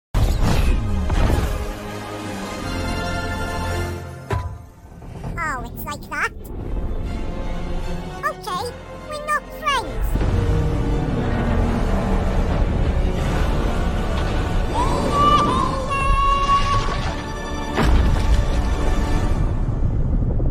Alvin as Robotnik